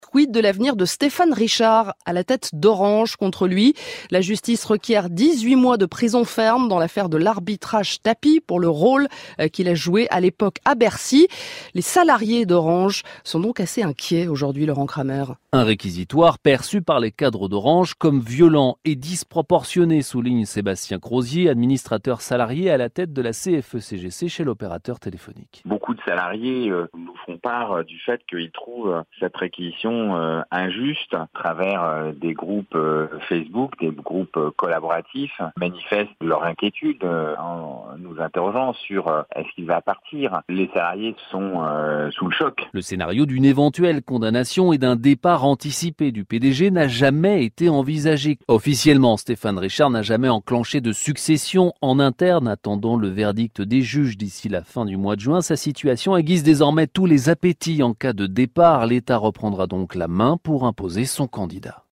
dans une interview sur France Inter